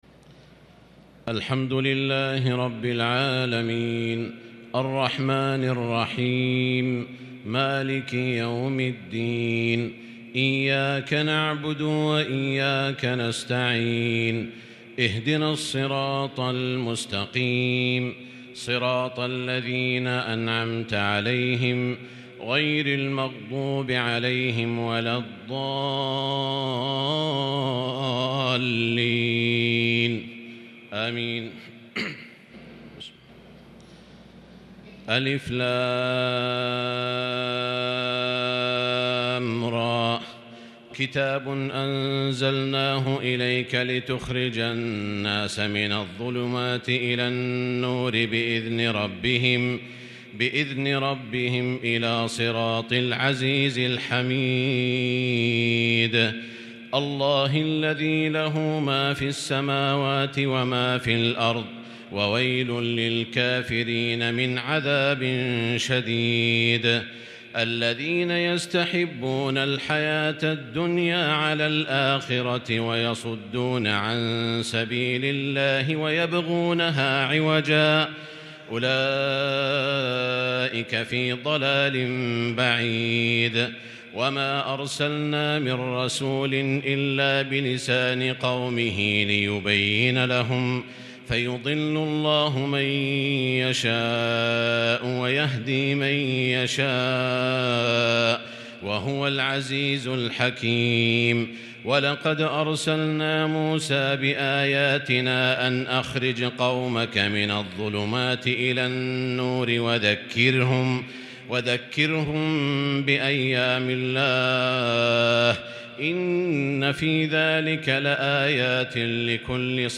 تراويح ليلة 30 رمضان 1442هـ سورة إبراهيم | Taraweeh 30st night Ramadan 1442H Surah Ibrahim > تراويح الحرم المكي عام 1442 🕋 > التراويح - تلاوات الحرمين